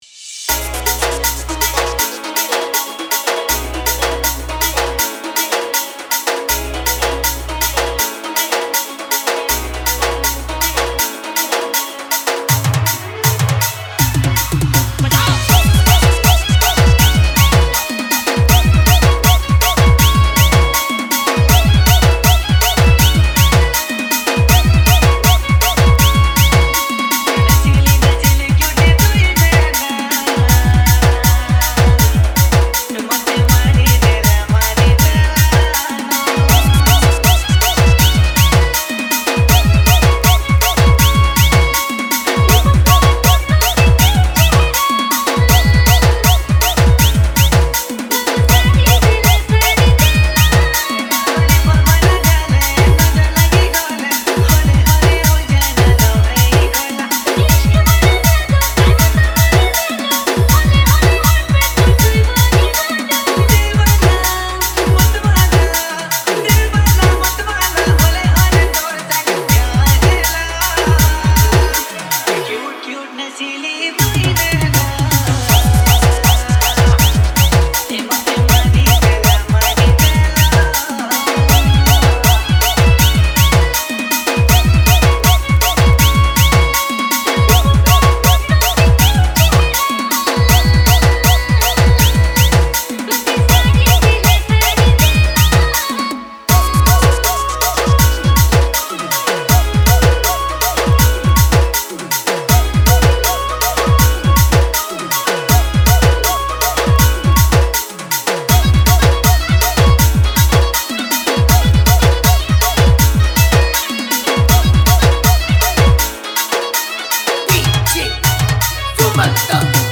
• Category: New Sambalpuri DJ Song 2025